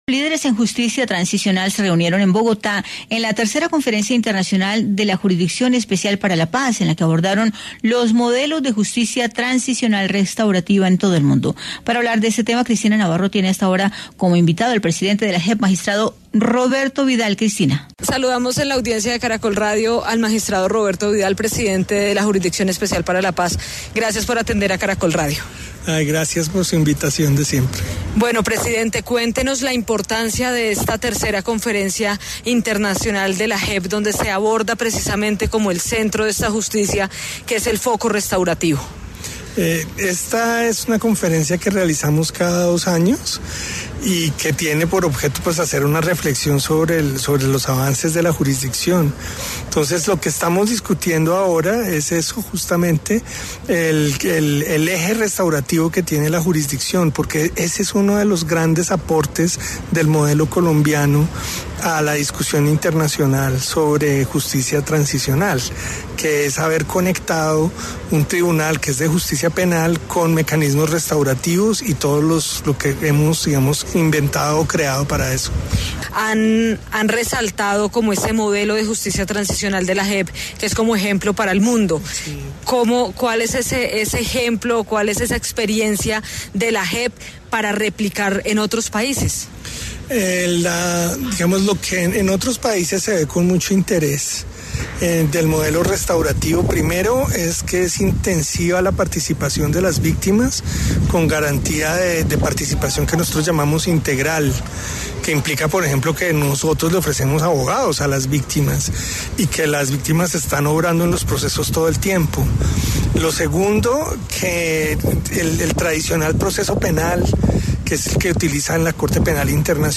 En diálogo con Caracol Radio, el magistrado Roberto Vidal, presidente de la JEP, explicó el modelo de Justicia Restaurativa